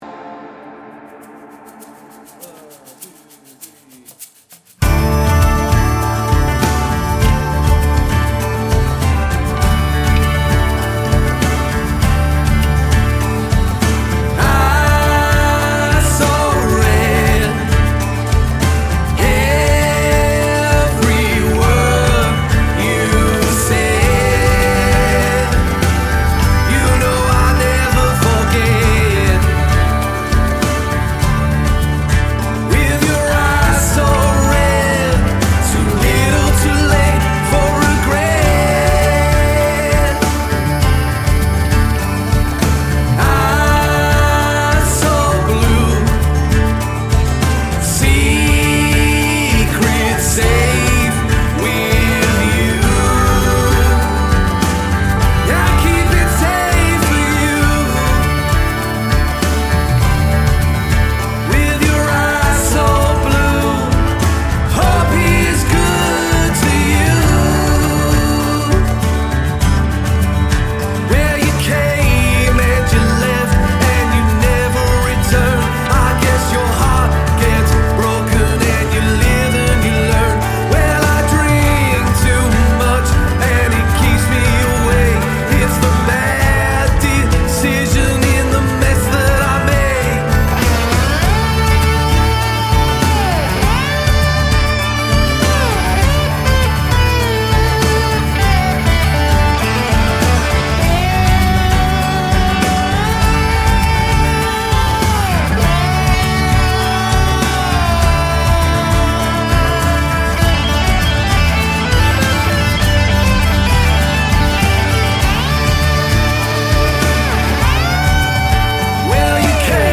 Male Vocal, Guitar, Keys, Lap Steel, Bass Guitar, Drums